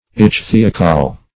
Search Result for " ichthyocol" : The Collaborative International Dictionary of English v.0.48: Ichthyocol \Ich"thy*o*col\, Ichthyocolla \Ich`thy*o*col"la\, n. [L. ichthyocolla, Gr.